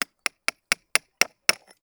TOOL_Chisel_Sequence_04_mono.wav